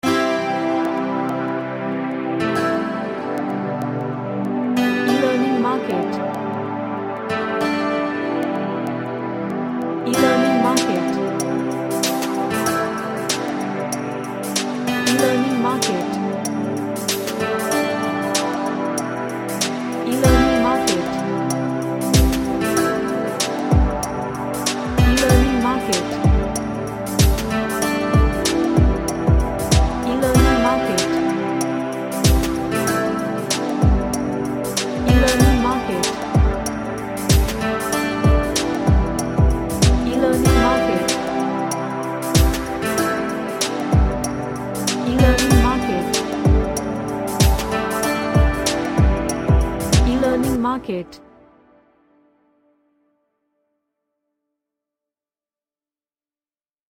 A Relaxing track with lots of ambience.
Relaxation / Meditation